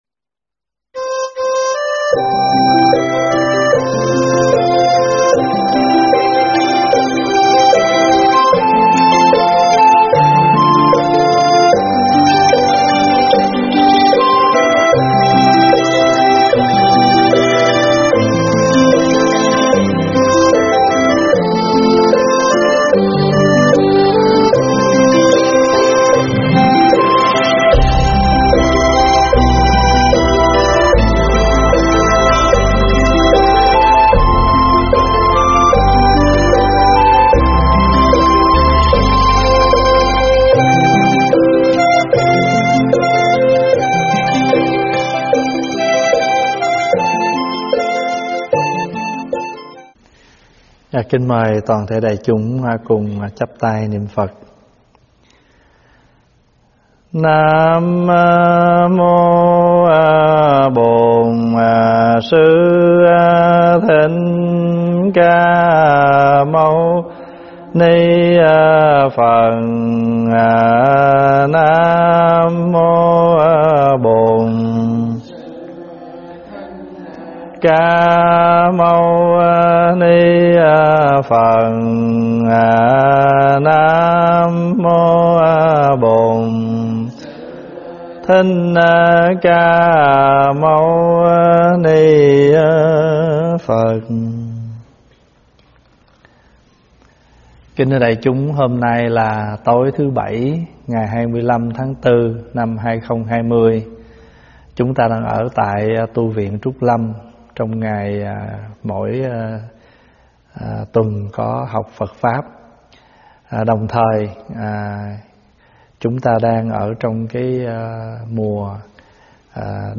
thuyết pháp
giảng tại Tv.Trúc Lâm